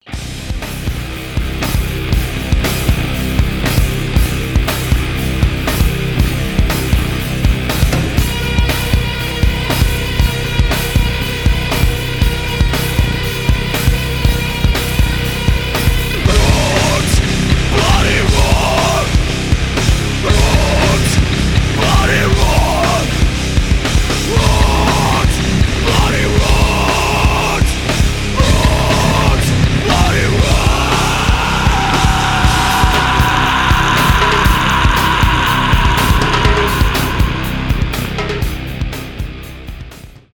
мощные , взрывные , метал , nu metal , жесткие , рок